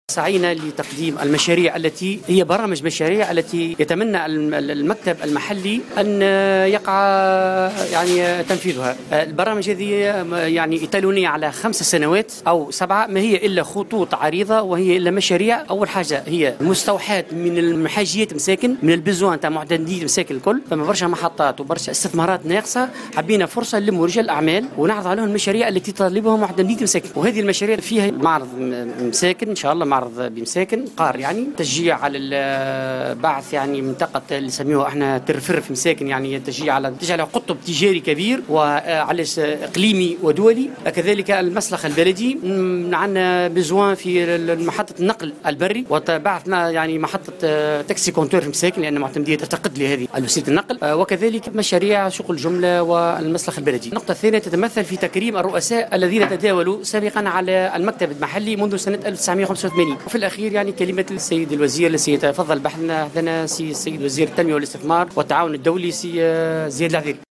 في تصريح للجوهرة أف أم، على هامش "ملتقى رجال الأعمال"